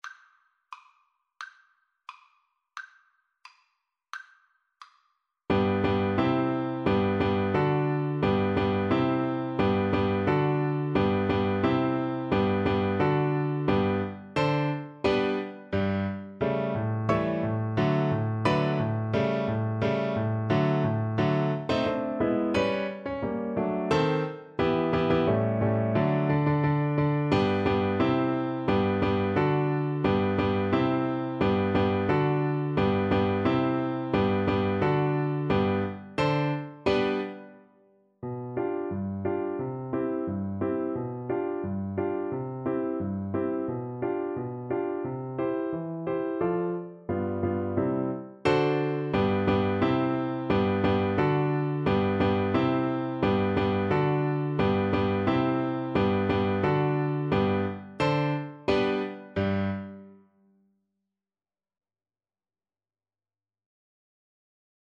Play (or use space bar on your keyboard) Pause Music Playalong - Piano Accompaniment Playalong Band Accompaniment not yet available reset tempo print settings full screen
~ = 88 Stately =c.88
G major (Sounding Pitch) (View more G major Music for Viola )
2/4 (View more 2/4 Music)
Classical (View more Classical Viola Music)